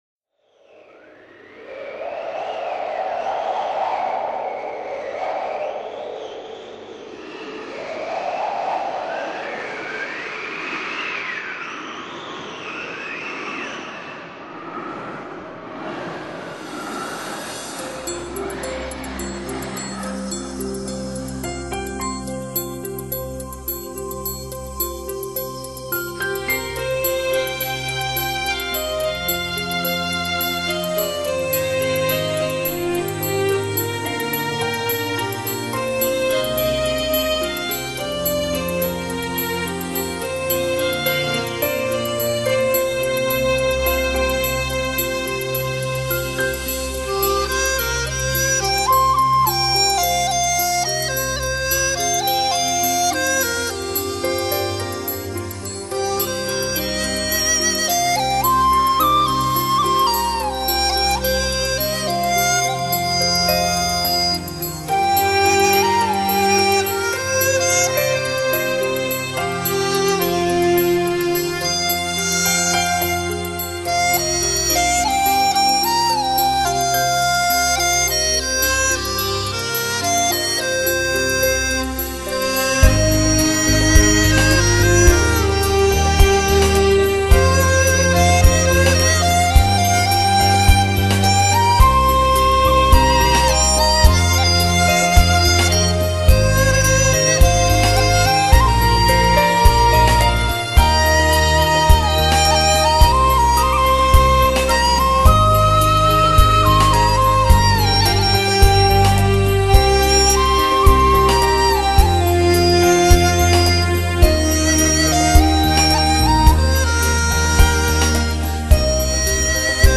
笛子演奏